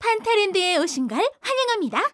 ui_sysmsg_welcome.wav